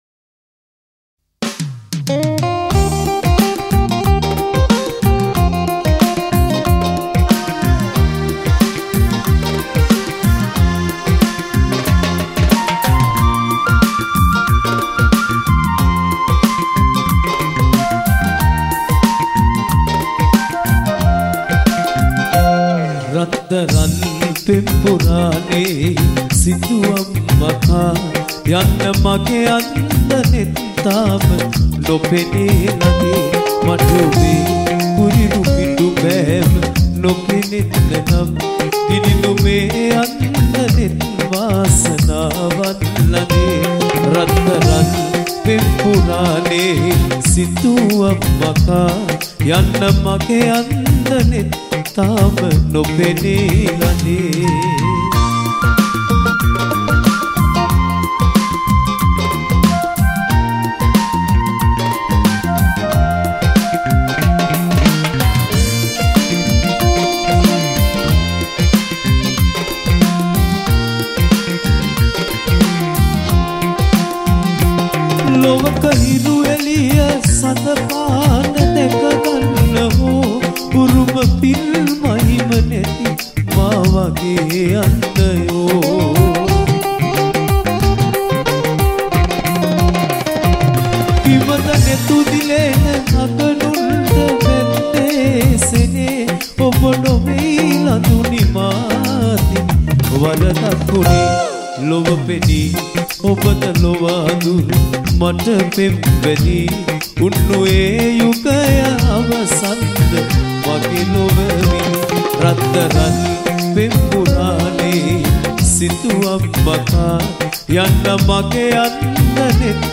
Drums
Bass
Lead guitar
Rhythm guitar
keyboard
Percussion